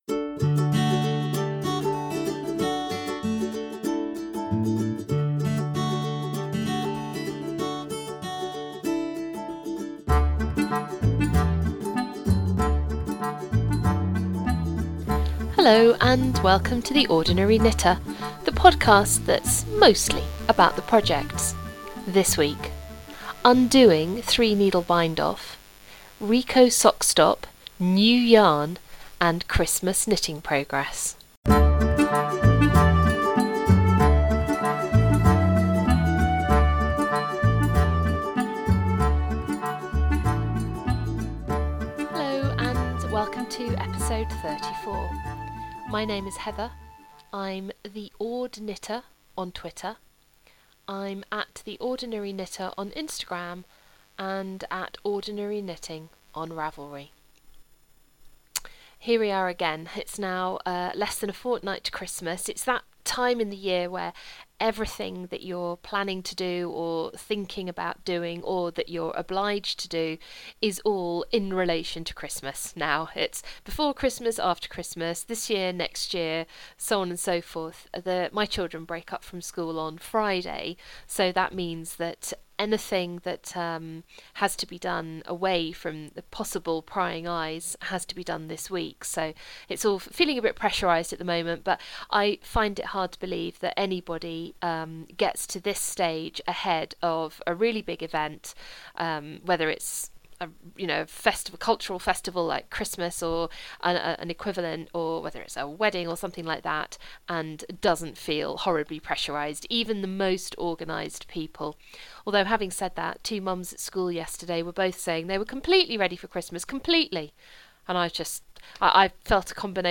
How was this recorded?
This week: knitting mistakes, undoing three needle bind off, Rico Sock Stop, new yarn and Christmas knitting progress. Sorry about the buzz again,